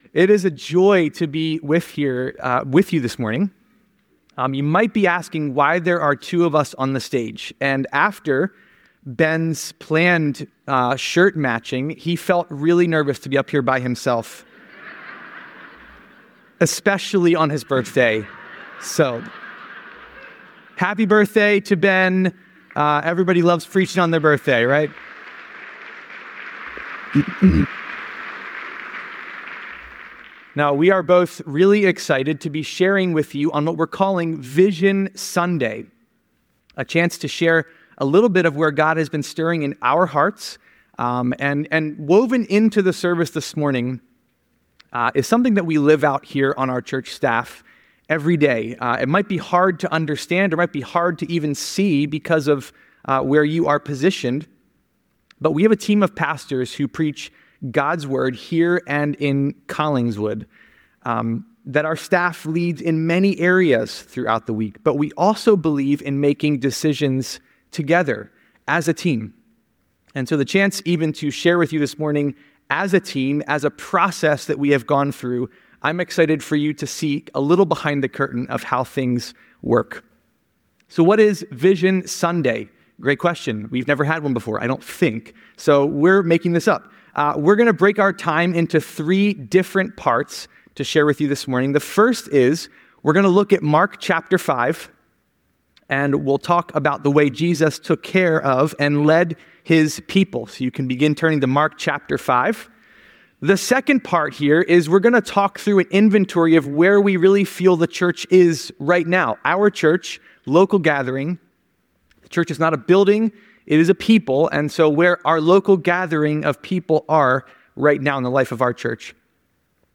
This sermon centers around 'Vision Sunday' at Fellowship.